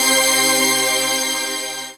DM PAD2-50.wav